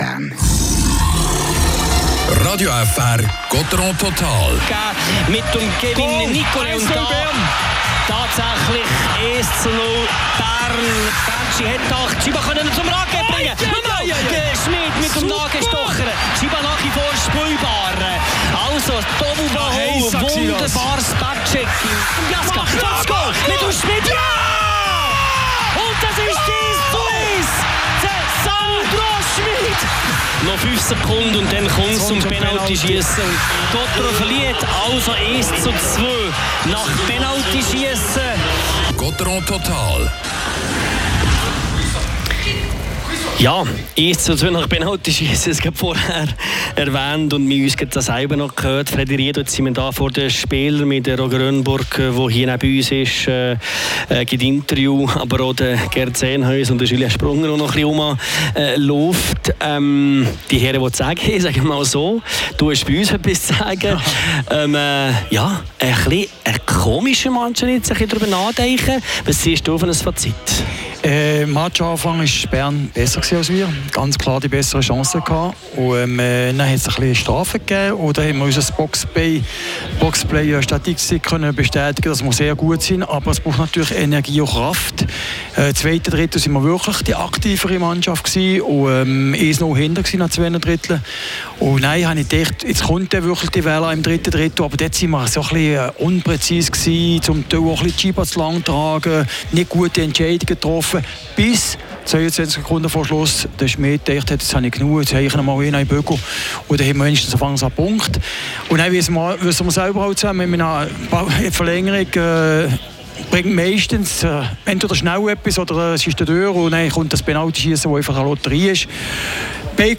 Die Drachen verlieren nach Penaltyschiesse und einem harzigen Spiel mit 2:1 gegen den SC Bern. Spielanalyse
Interviews